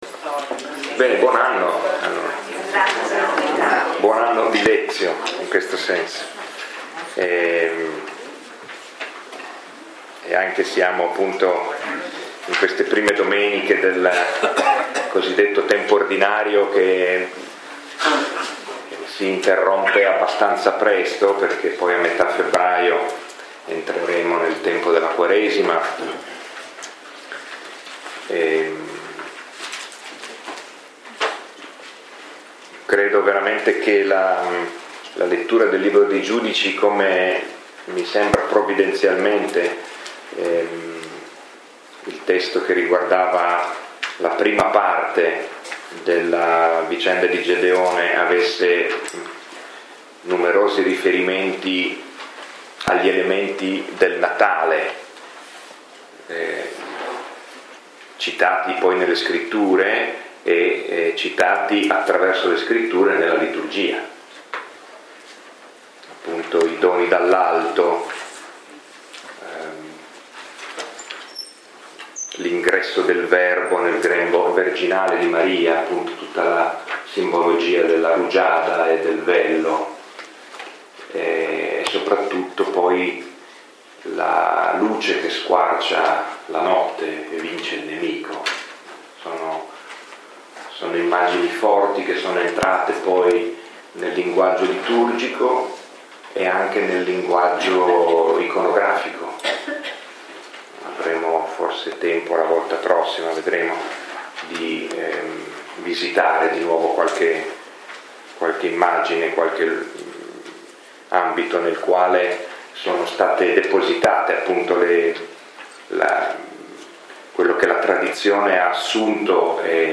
Lectio 4 – 21 gennaio 2018